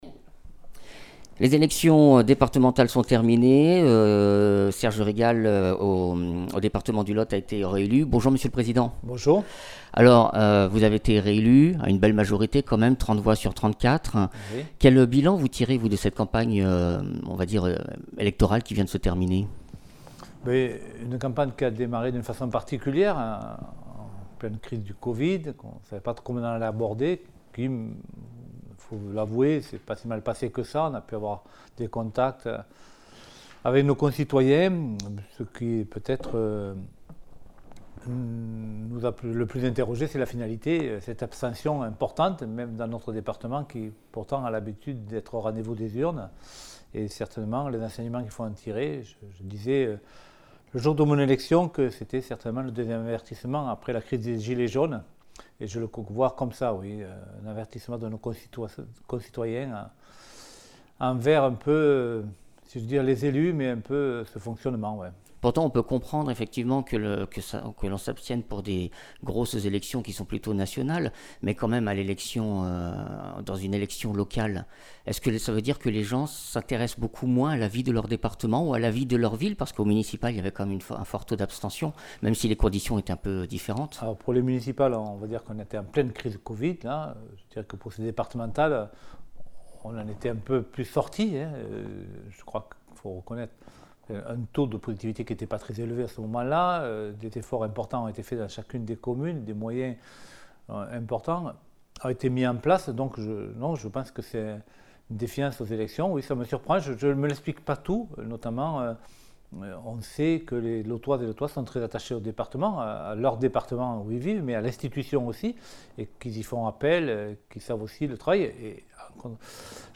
Interviews
Invité(s) : Serge Rigal, Président du département du Lot